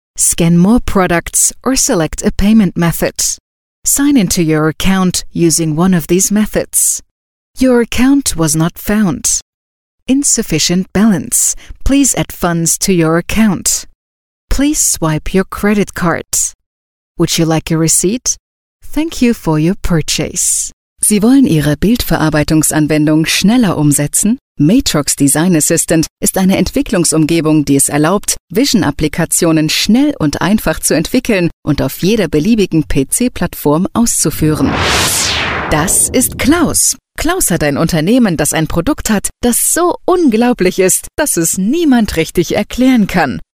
Native speaker Female 30-50 lat
angielski, niemiecki · Turnaround: 24-48h · Powitanie tel. Reklama Narracja do filmu Audiobook